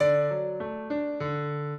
piano
minuet15-3.wav